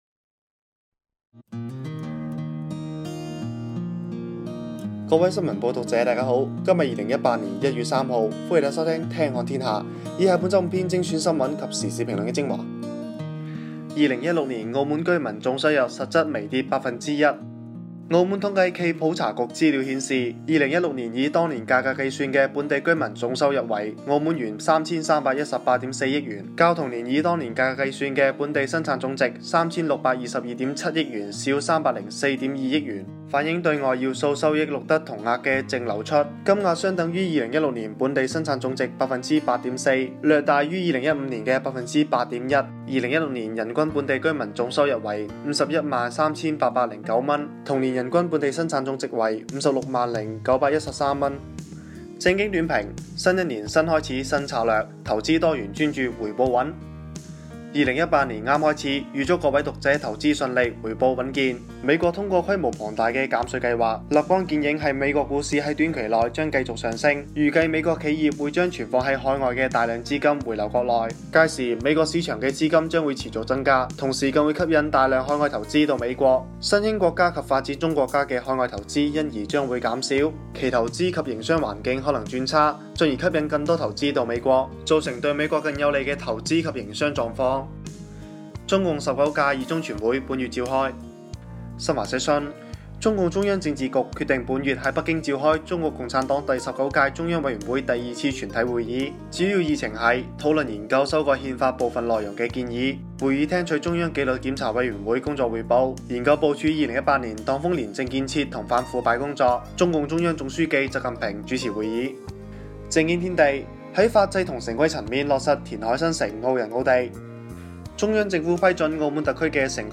[語音播報]新聞及時事評論精華（粵語）